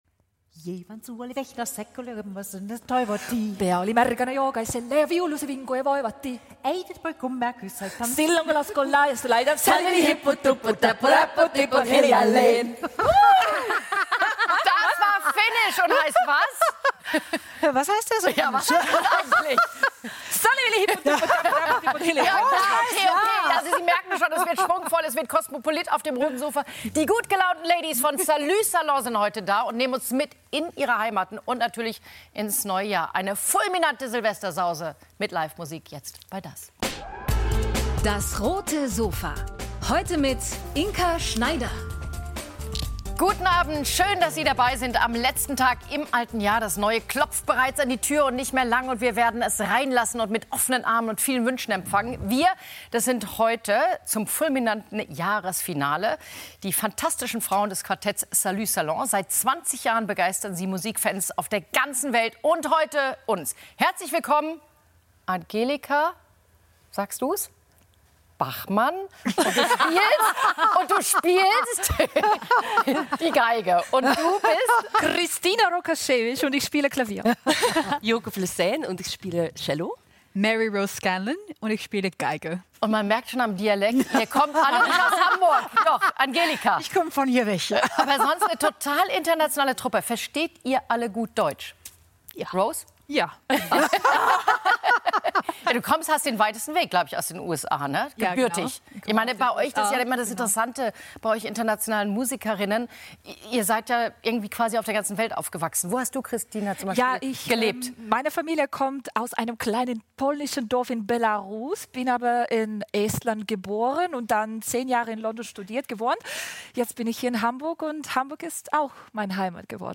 Kammermusik-Quartett Salut Salon auf dem Roten Sofa ~ DAS! - täglich ein Interview Podcast